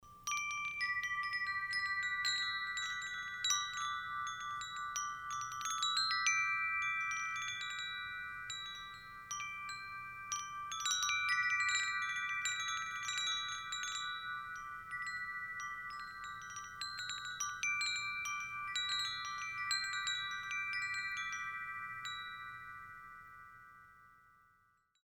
ウィンドチャイム 『ハッピーバースデーの音色』 ウッドストックチャイムス WPI045
■パイプの本数：6本
■材質：アルミニウム